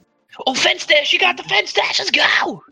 ghbot - Discord version of greenhambot -- currently just plays meme sfx in voice channels + static text commands
fencedash.mp3